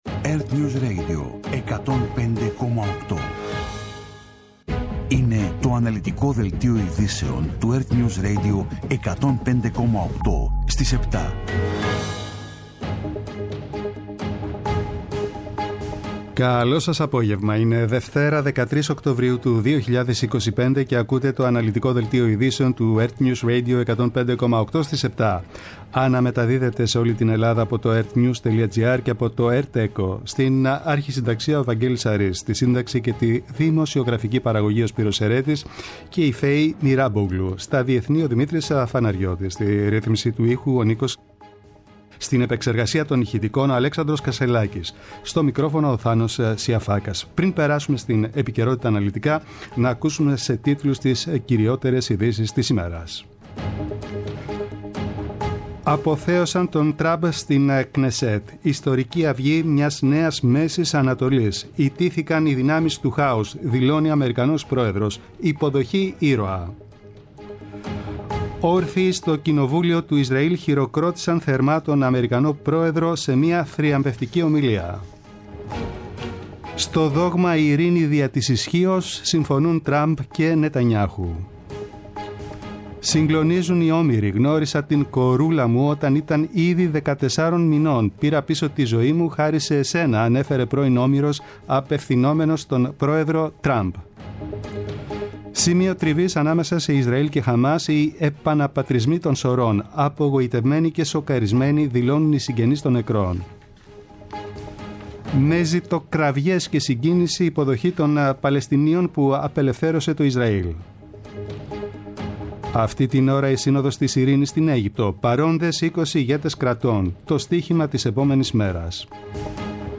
Το αναλυτικό ενημερωτικό μαγκαζίνο στις 19:00.
Με το μεγαλύτερο δίκτυο ανταποκριτών σε όλη τη χώρα, αναλυτικά ρεπορτάζ και συνεντεύξεις επικαιρότητας.